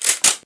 m3_bolt.wav